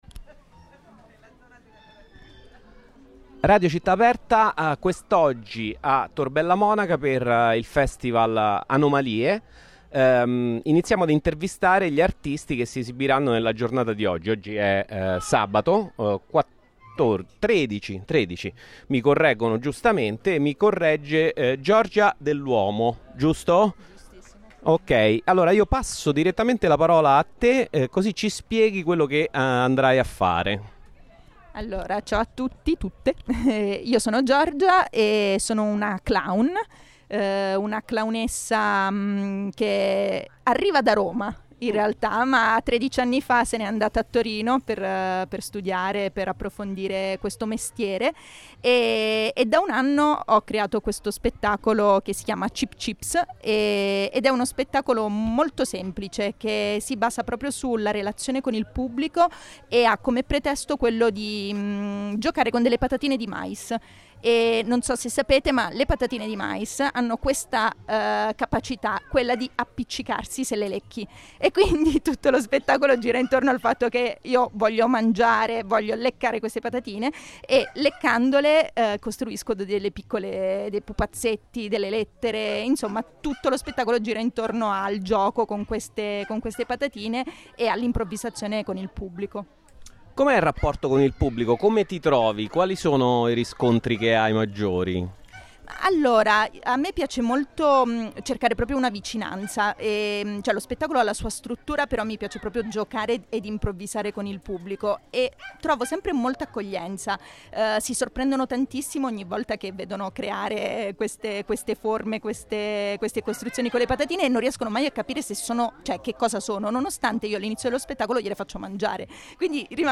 Radio città aperta sarà presente con la sua radio anomala.